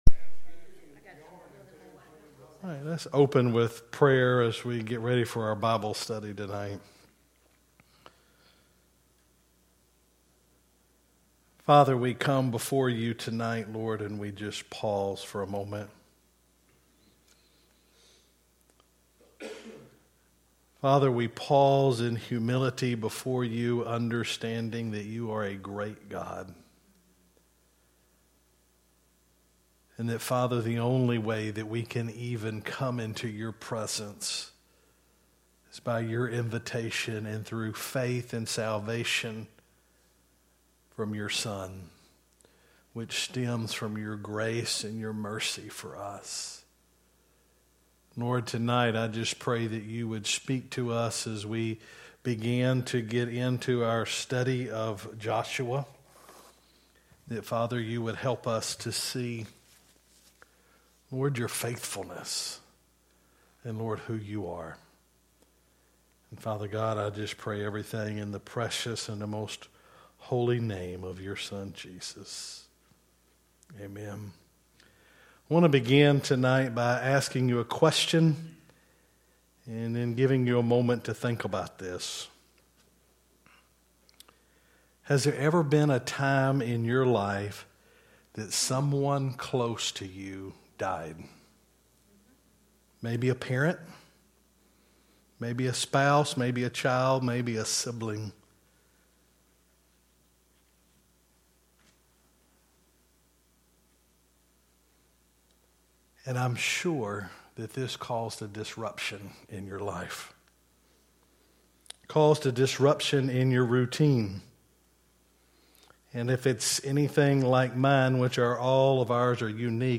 Sermons by Mt. Olivet Baptist Church Stanley, LA